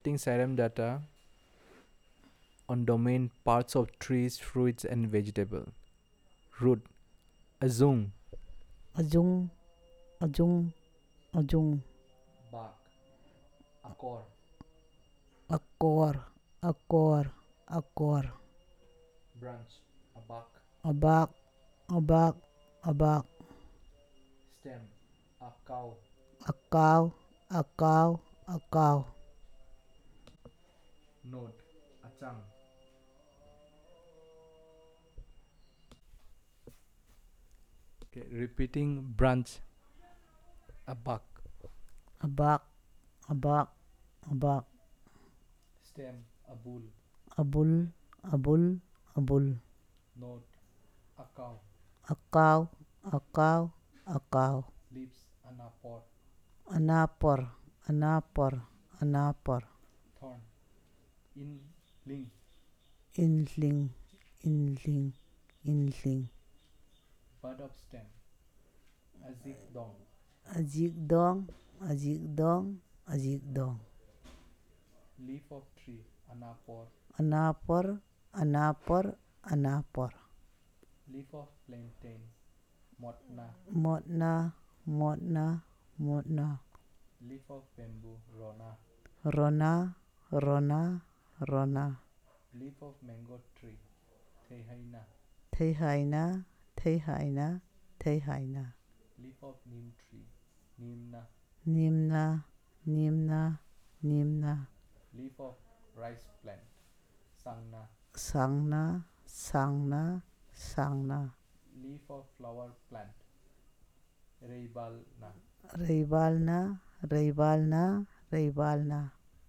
dc.description.elicitationmethodInterview method
dc.type.discoursetypeElicitation